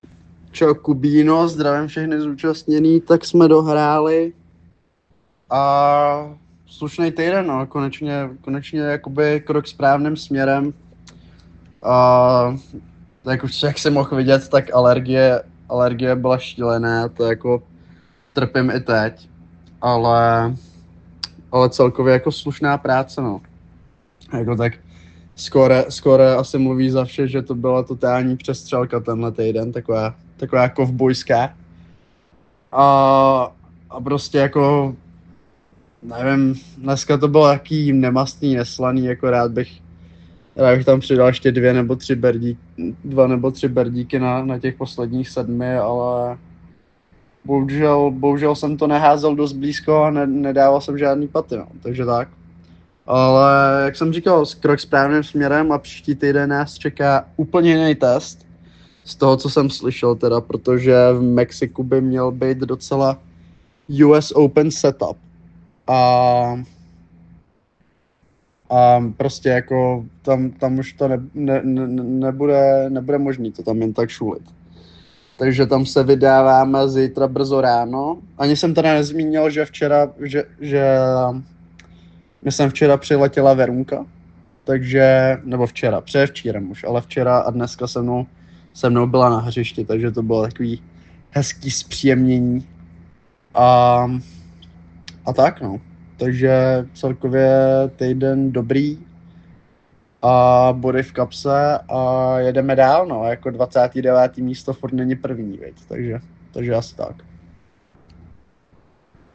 Komentář